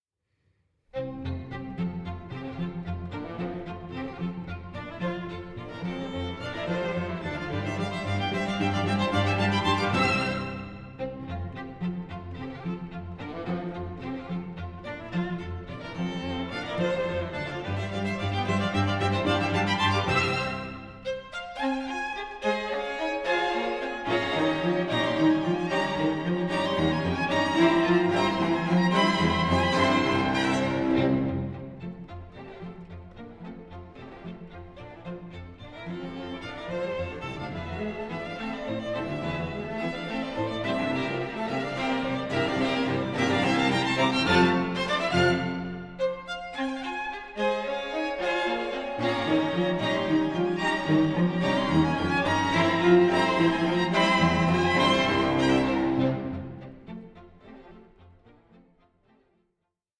full, “orchestral” and rich in harmonies.
combining chamber-musical intimacy with “symphonic” depth.